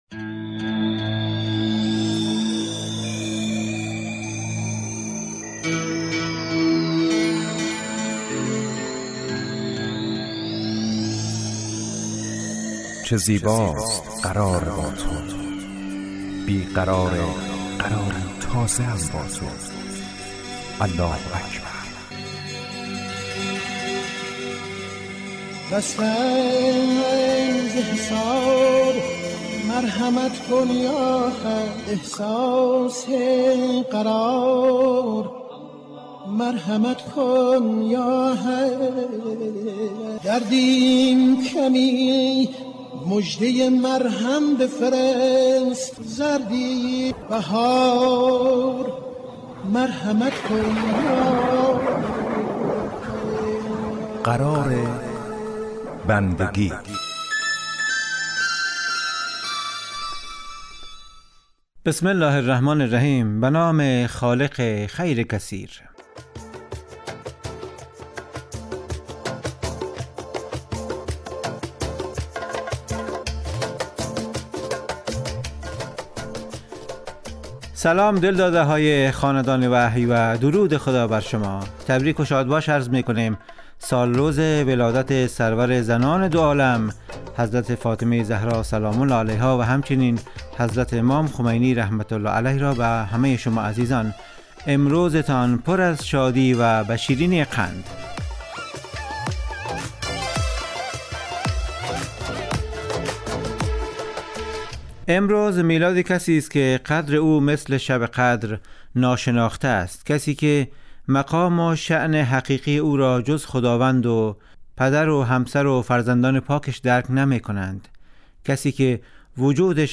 قرار بندگی برنامه اذانگاهی در 30 دقیقه هر روز ظهر پخش می شود.